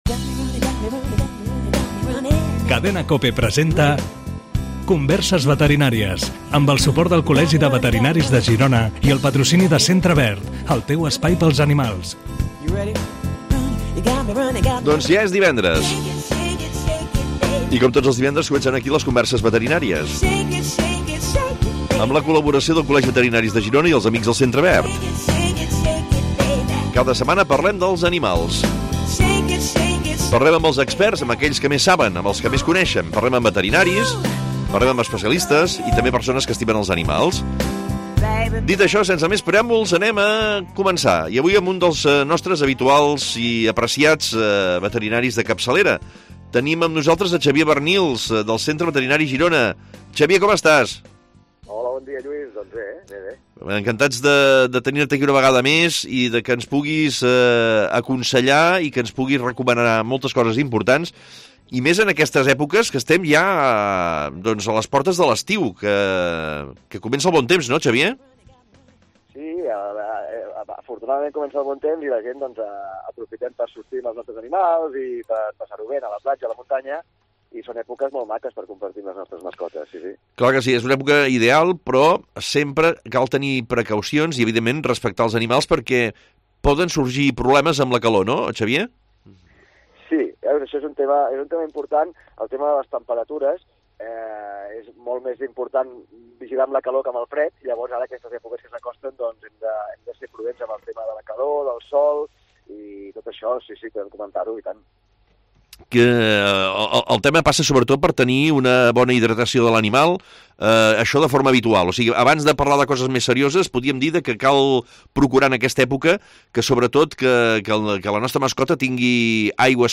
Es contesta per ràdio a les preguntes de propietaris de gossos i gats.